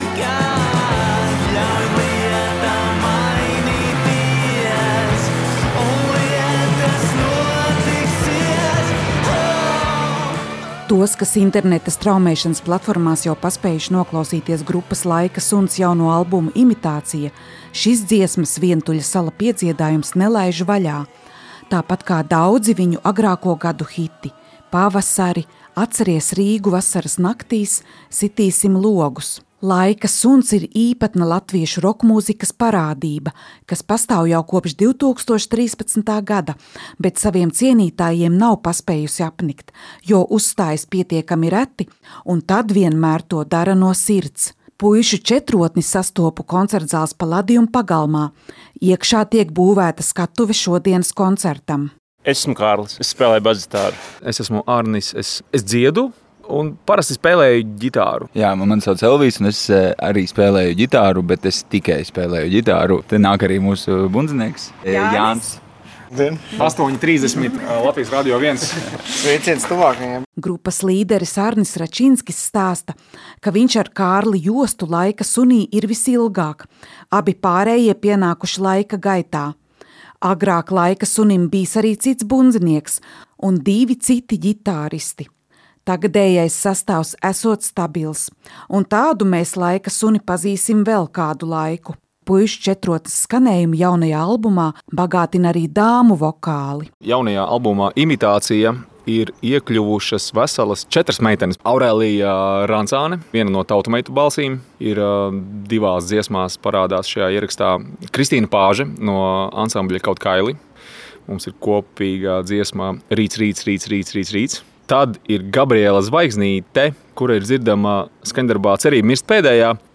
Viņi savai mūzikai izdomājuši definīciju, ar ko atšķirties no visiem citiem: pilsētas roks.